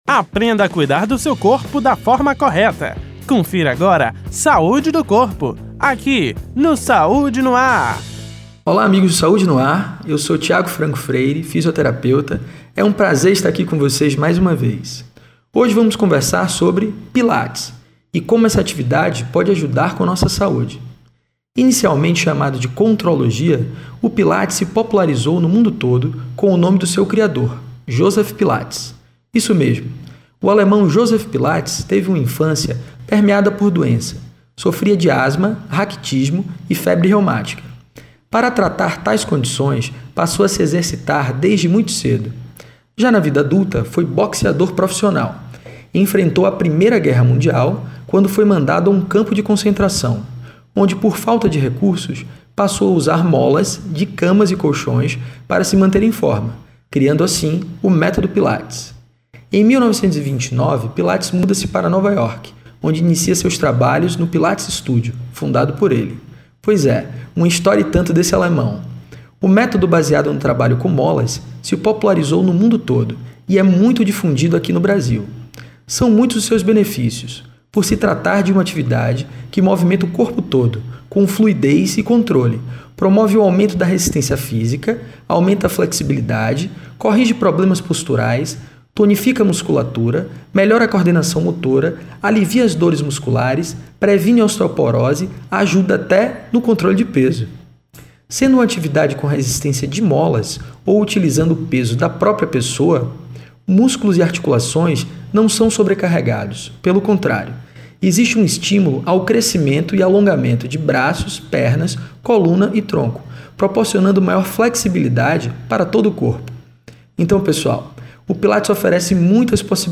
O assunto foi tema do Quadro: Saúde do Corpo exibido toda segunda-feira no Programa Saúde no ar, veiculado pela Rede Excelsior de Comunicação: AM 840, FM 106.01, Recôncavo AM 1460 e Rádio Saúde no ar / Web.